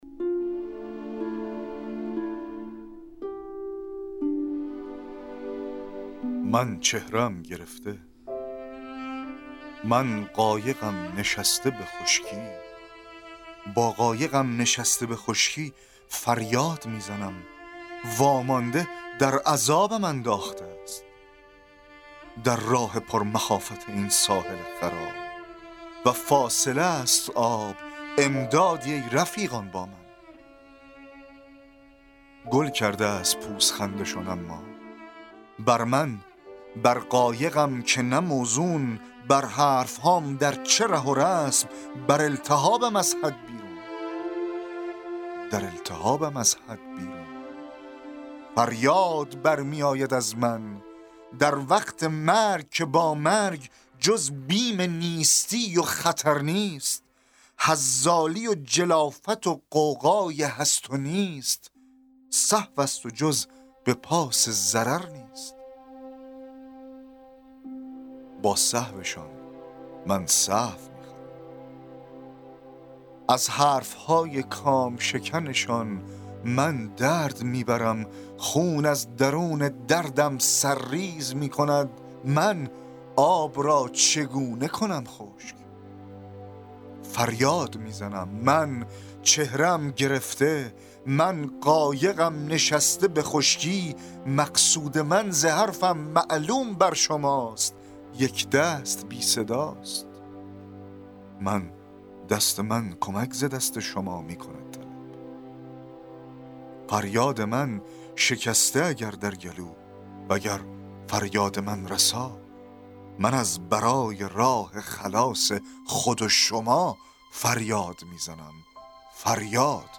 دکلمه شعر قایق